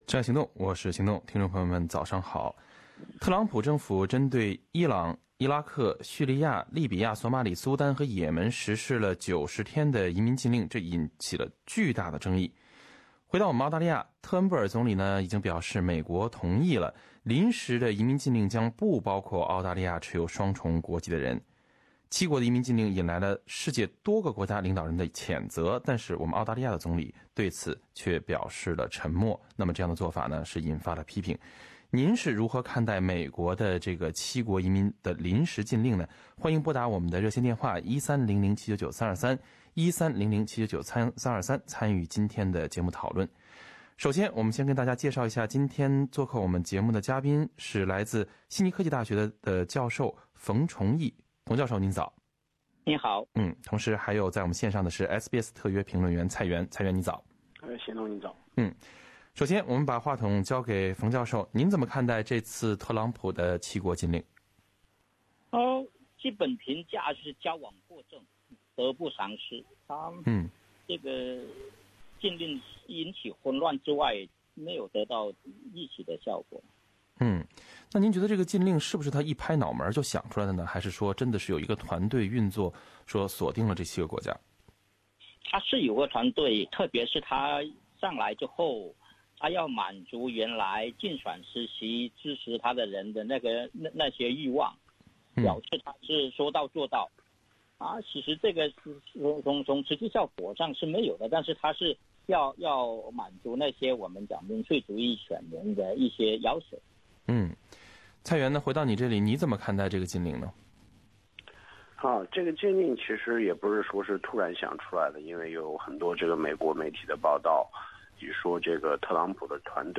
正在行动：听众热议特朗普七国移民禁令